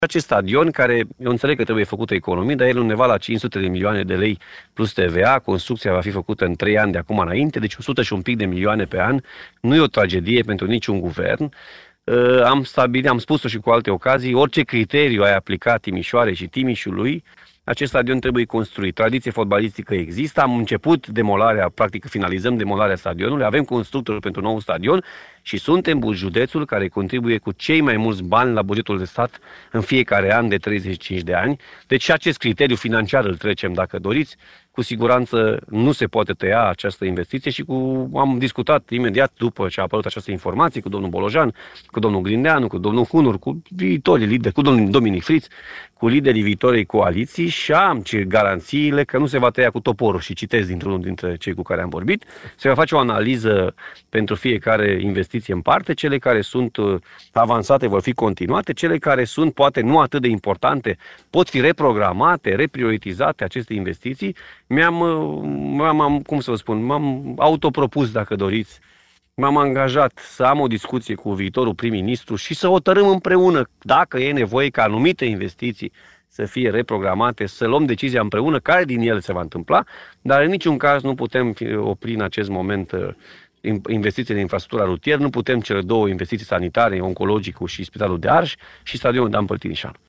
Președintele Consiliului Județean Timiș a fost invitat la ediția de sâmbătă a emisiunii Arena Radio.